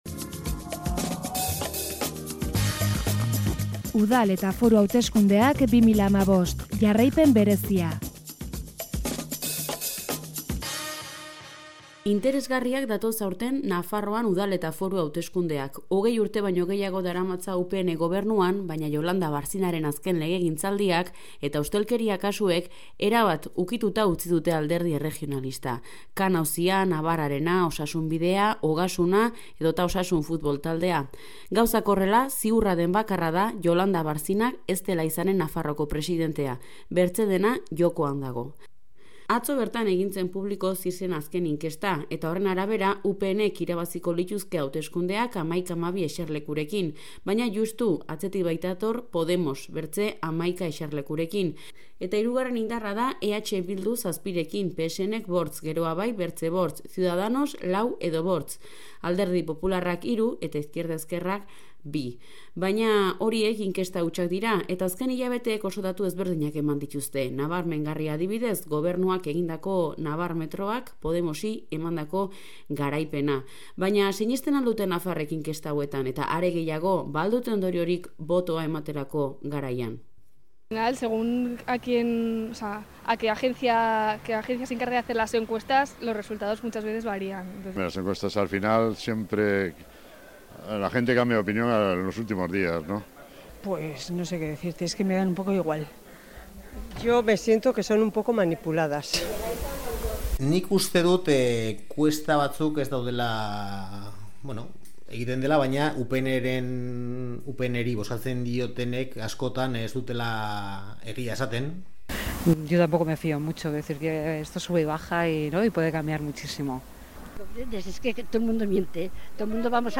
Iruñeko kaleetatik ibili gara gaur herritarrekin solasean.